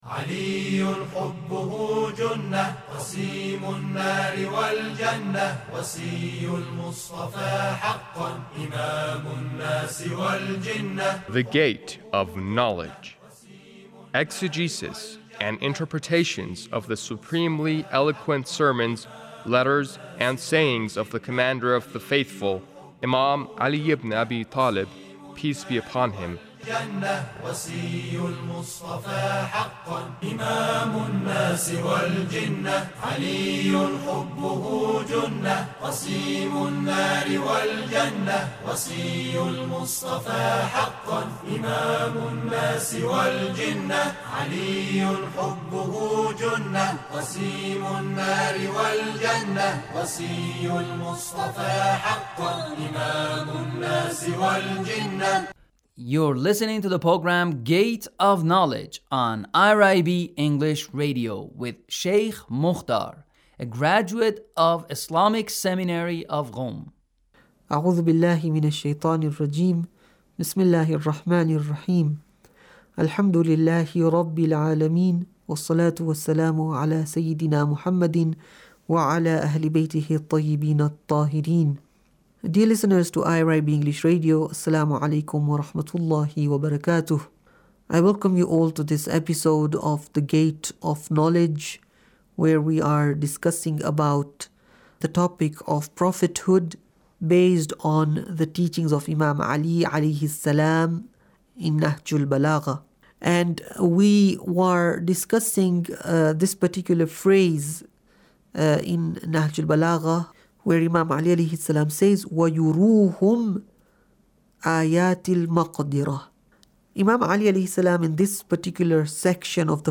Sermon 2 -